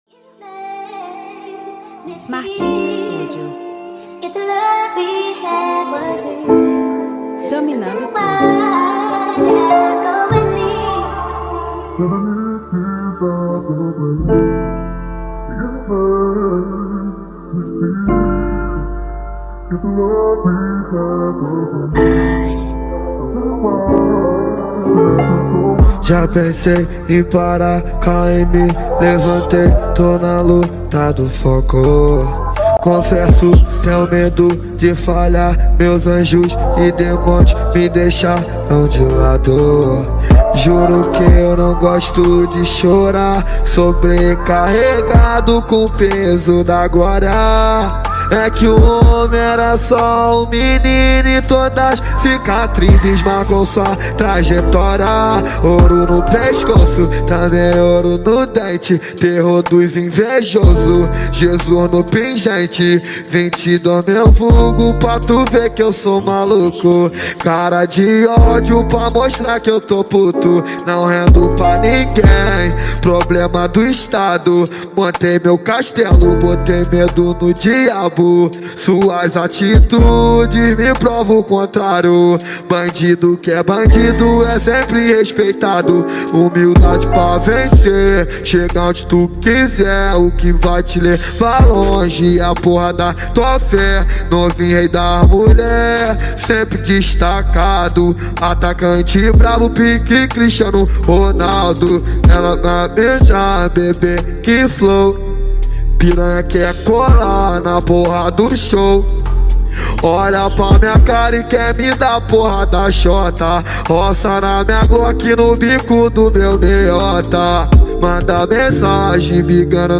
2024-07-26 13:13:22 Gênero: Trap Views